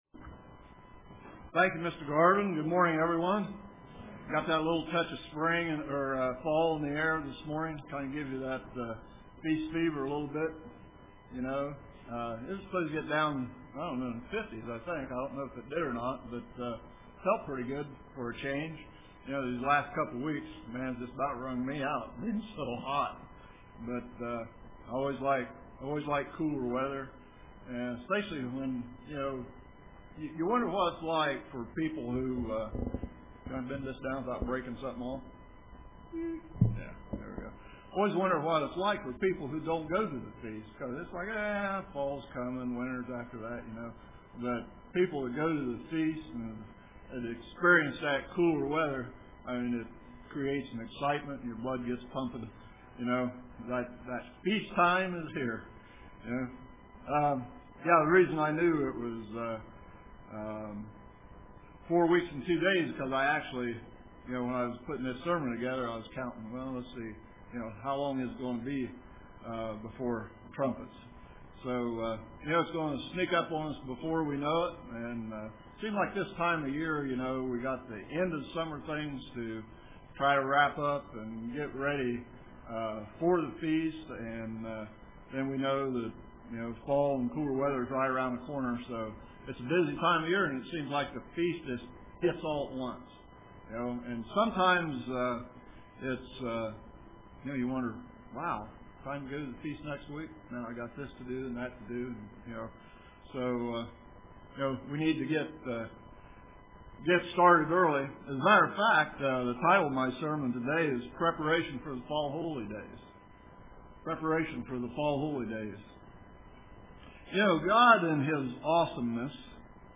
Preparations for the Fall Holy Days UCG Sermon Studying the bible?